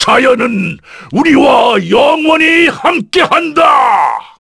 Kaulah-Vox_Victory_kr.wav